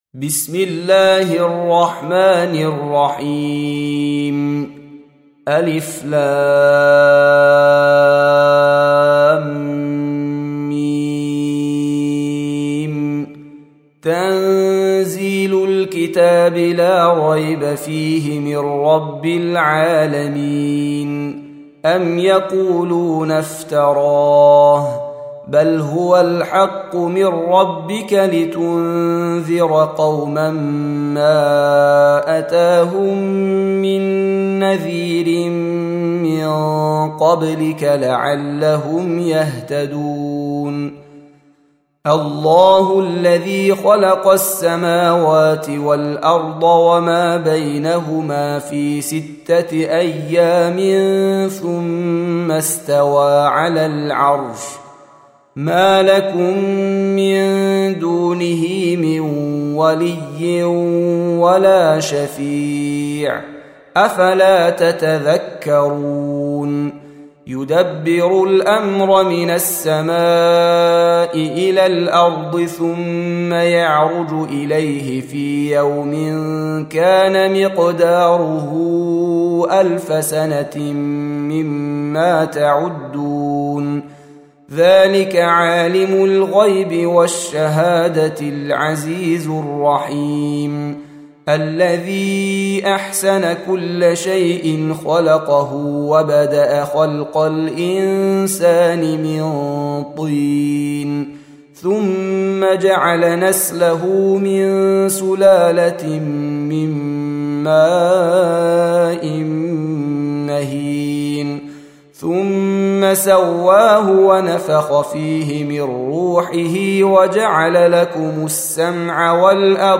Surah Repeating تكرار السورة Download Surah حمّل السورة Reciting Murattalah Audio for 32.